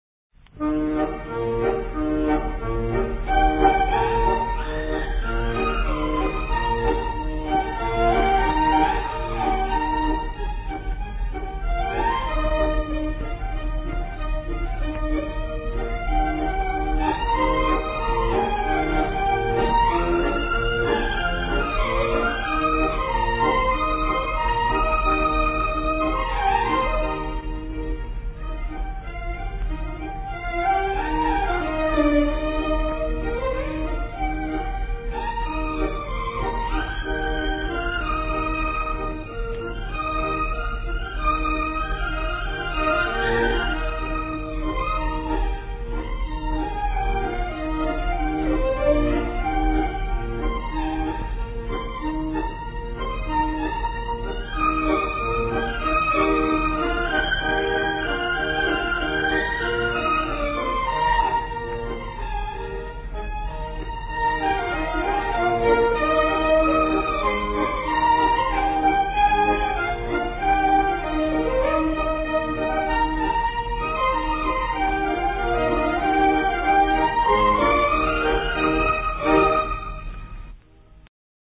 演奏：洞簫
一張一弛，音樂富有動力。